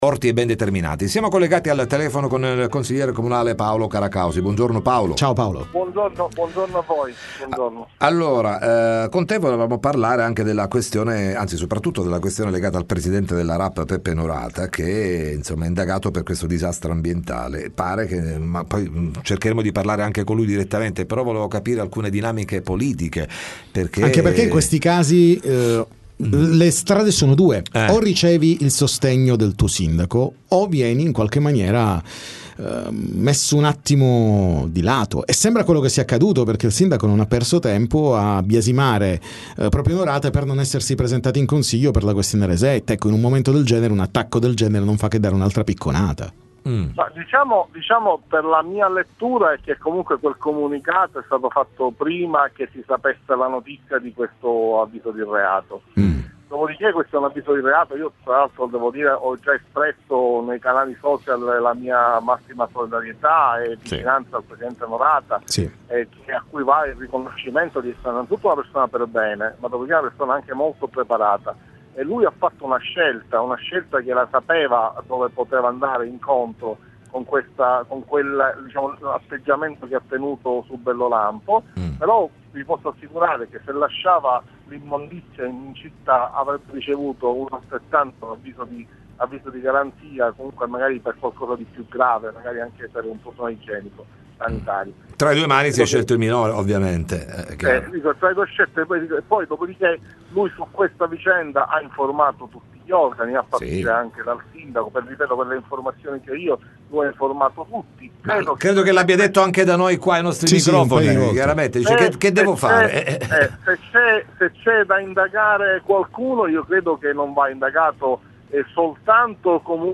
Interviste Time Magazine